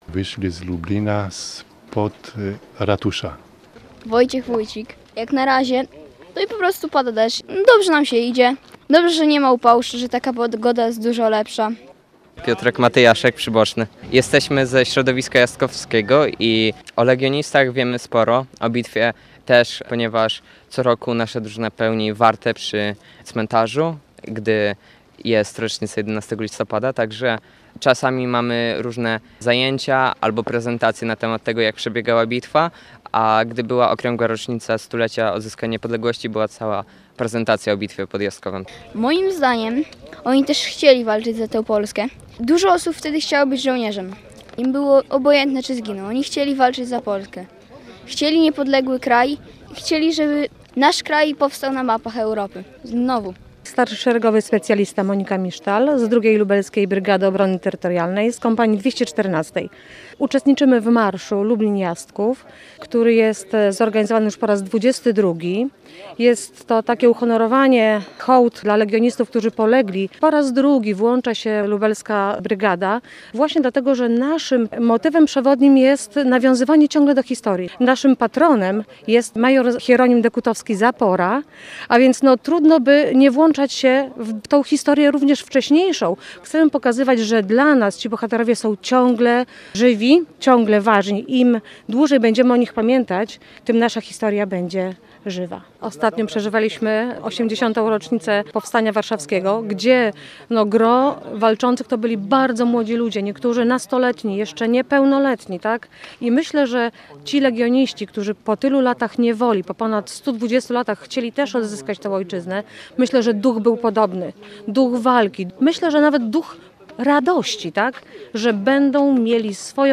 Naszymi przewodnikami są między innymi nauczyciele i uczniowie do tej pory uczący się w szkole-pomniku, którą legioniści wystawili swoim kolegom.
Reportaż powstał w 100-lecie bitwy z 1915 roku.